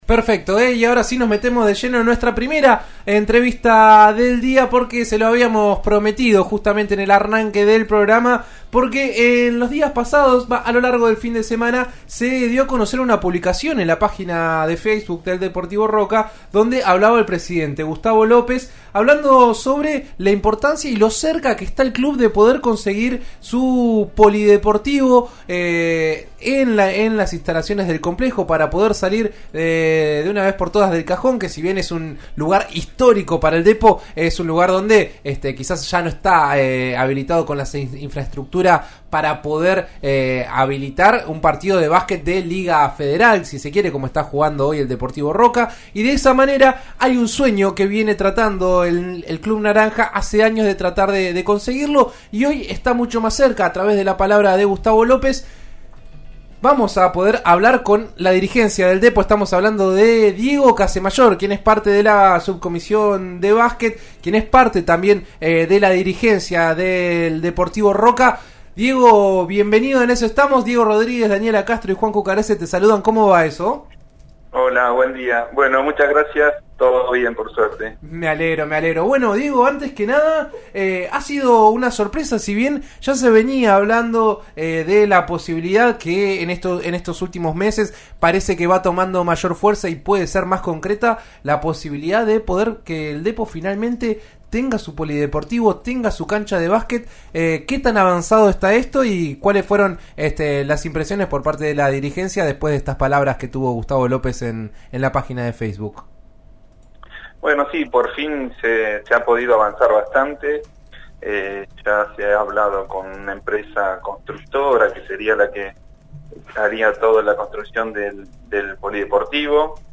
En eso estamos de RN Radio (89.3 en Neuquén y 89.9 en Roca) dialogó